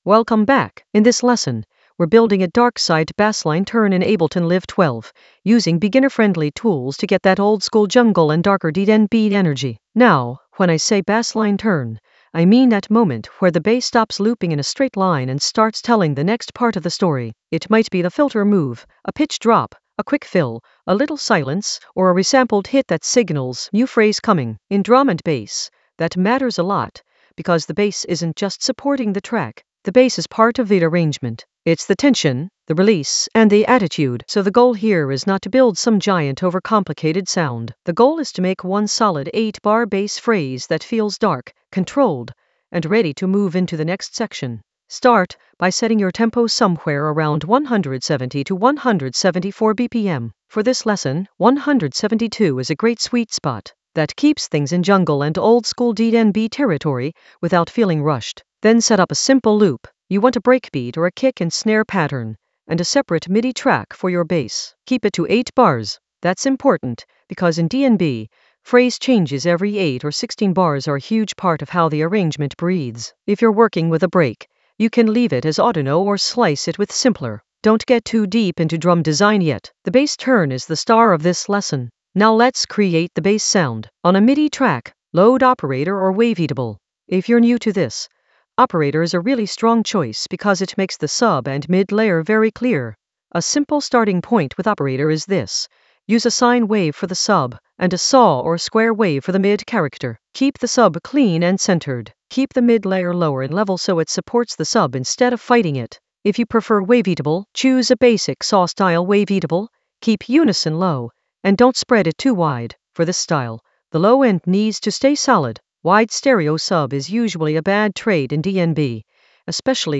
An AI-generated beginner Ableton lesson focused on Darkside a bassline turn: design and arrange in Ableton Live 12 for jungle oldskool DnB vibes in the Automation area of drum and bass production.
Narrated lesson audio
The voice track includes the tutorial plus extra teacher commentary.